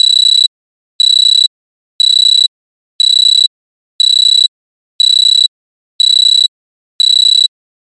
Download Digital Alarm Buzzer sound effect for free.
Digital Alarm Buzzer